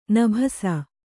♪ nabhasa